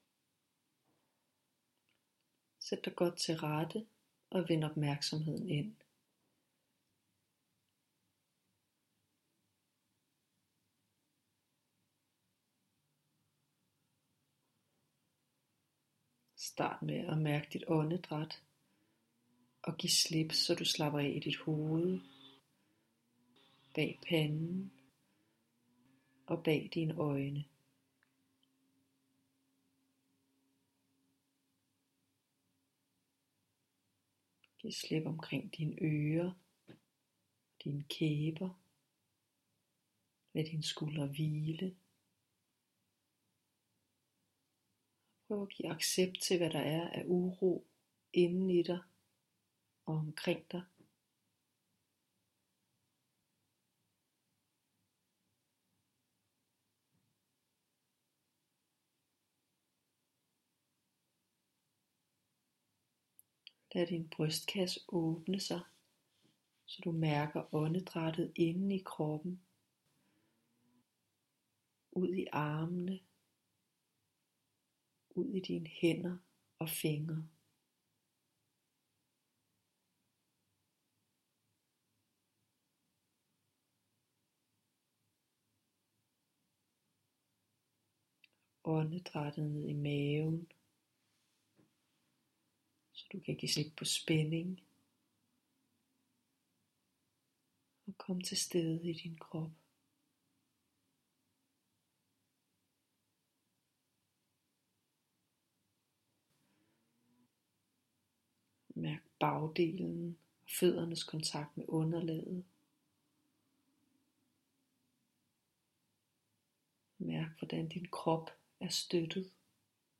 Her er en meditation, 5 1/2 minuts fordybelse du kan bruge til at mærke dig selv og finde ind til det rum af nærvær og accept der ALLEREDE er tilstede i dig.